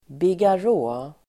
Ladda ner uttalet
Uttal: [bigar'å:]